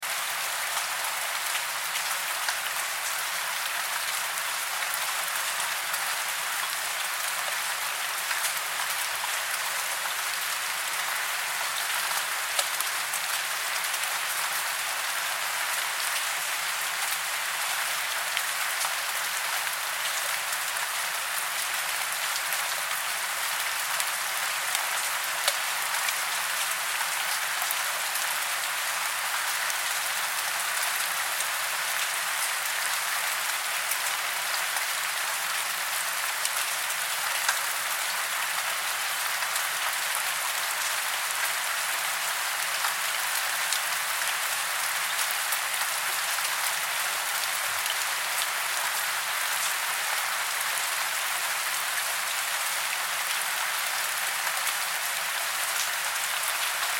دانلود آهنگ باران 25 از افکت صوتی طبیعت و محیط
دانلود صدای باران 25 از ساعد نیوز با لینک مستقیم و کیفیت بالا
جلوه های صوتی